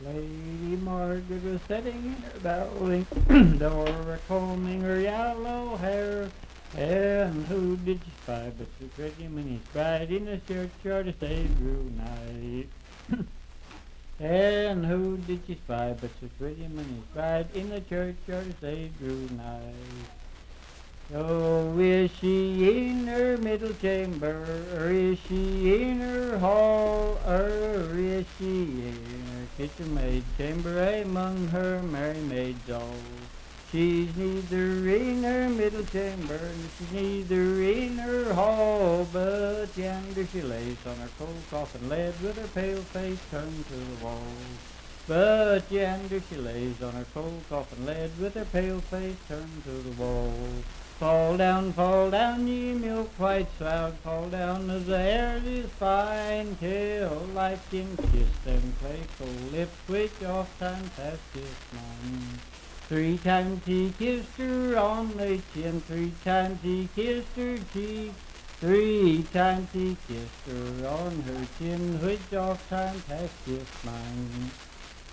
Unaccompanied vocal music
in Riverton, W.V.
Verse-refrain 5(4).
Voice (sung)